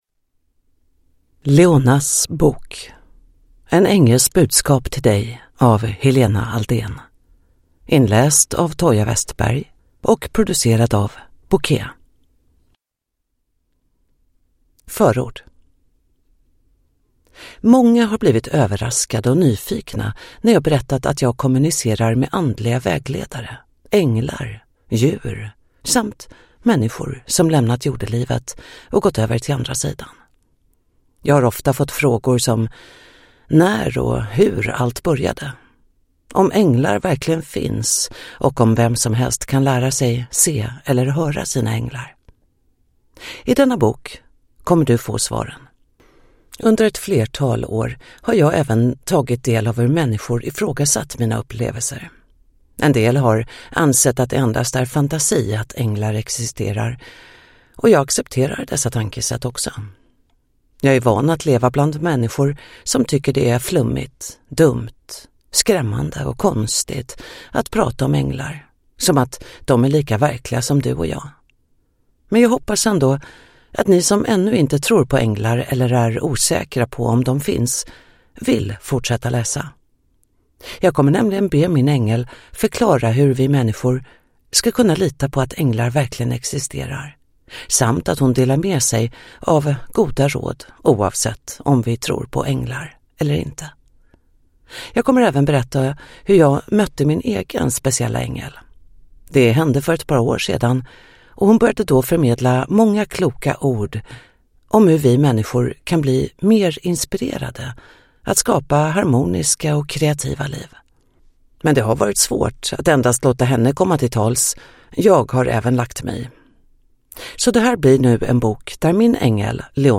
Leonahs bok : en ängels budskap till dig (ljudbok